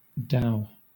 Ääntäminen
Vaihtoehtoiset kirjoitusmuodot dough dhow Ääntäminen Southern England: IPA : /daʊ/ Haettu sana löytyi näillä lähdekielillä: englanti Kieli Käännökset suomi kukoistaa , vaurastua Dow on sanan dough vanhentunut kirjoitusmuoto.